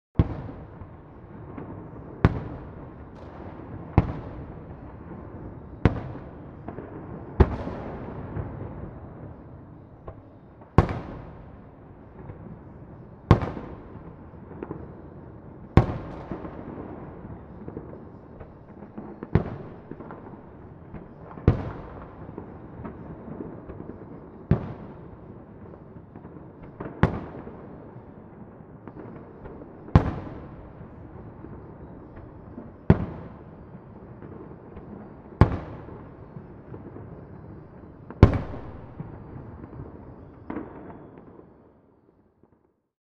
Constant Powerful Fireworks Explosions Sound Effect
Deep and powerful fireworks explosions rumble in the distance, creating an intense and immersive sound.
Constant-powerful-fireworks-explosions-sound-effect.mp3